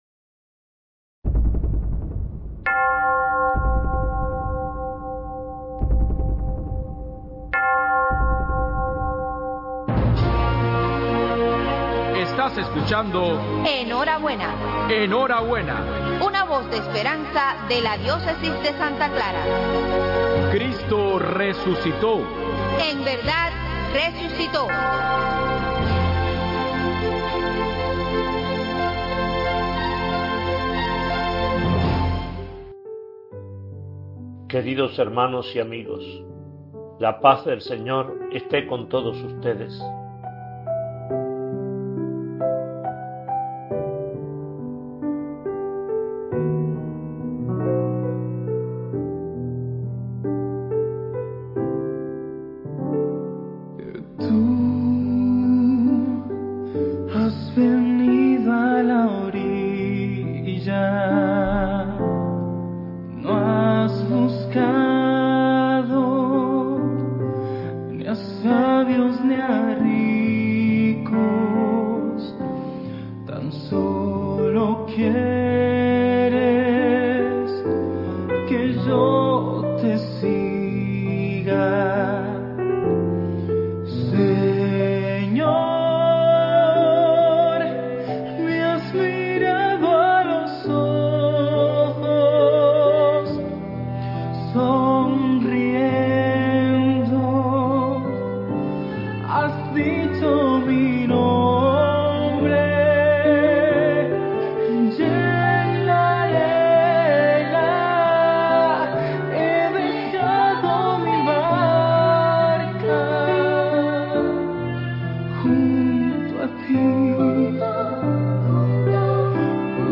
QUINTO DOMINGO DE PASCUA: MENSAJE RADIAL DE MONS. ARTURO GONZÁLEZ AMADOR, OBISPO DE SANTA CLARA: MENSAJE RADIAL DE MONS.